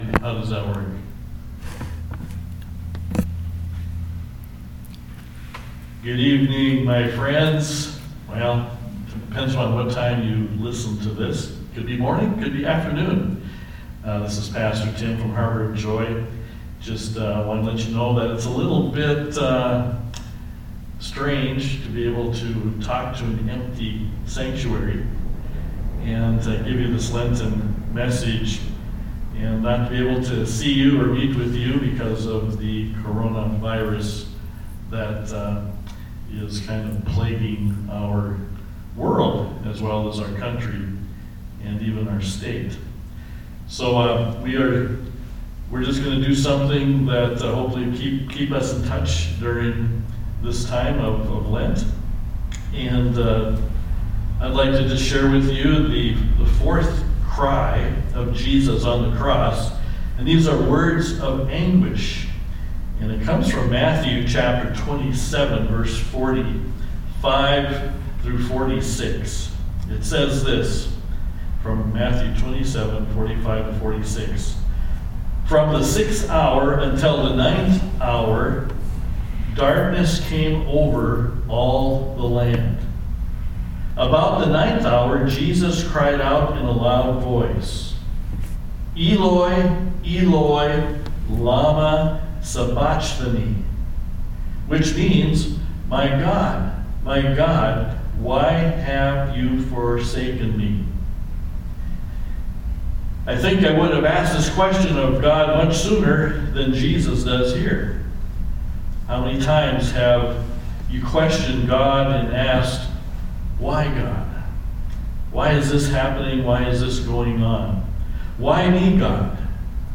Bible Text: Matthew 27: 45-46 | Preacher